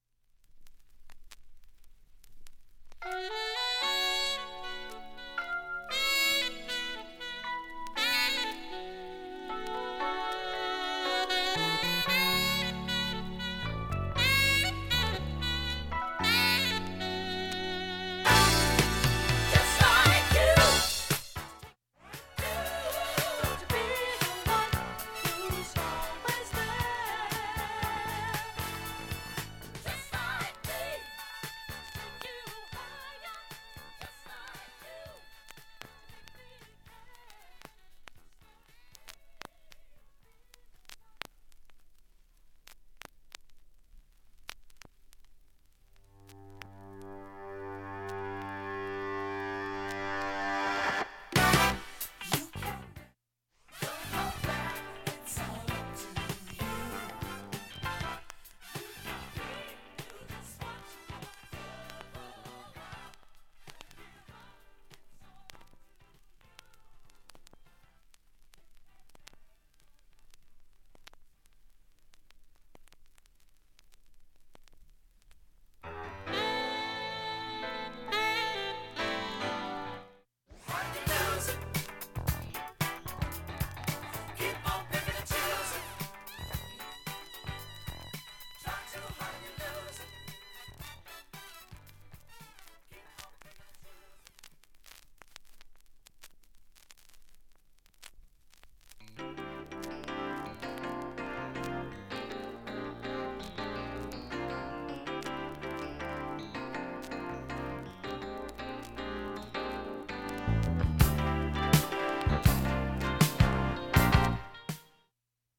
普通に聴けます音質良好全曲試聴済み。
A-1イントロ部に数回チャッという音出ます。
かすかなプツが9回出ます。
単発のかすかなプツが６箇所
80'sダンクラAOR名盤